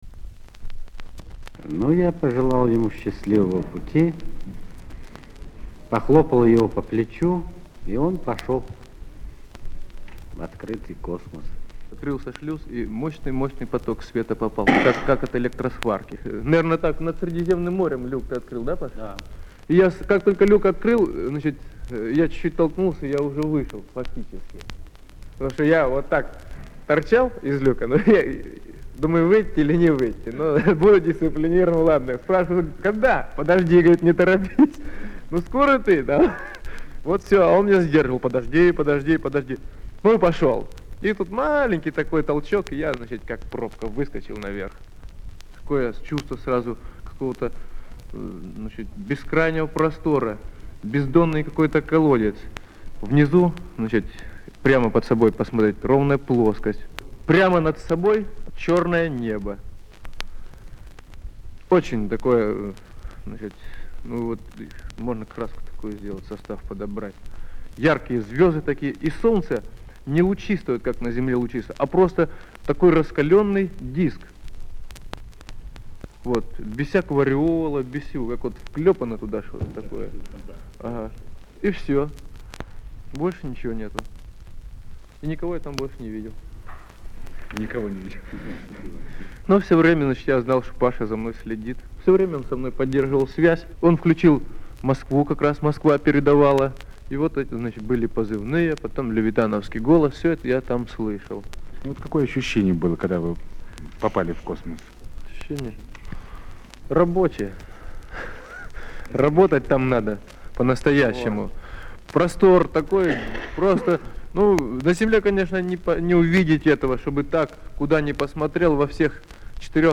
П.Беляев, А.Леонов. Первое интервью после полёта, взятое на космодроме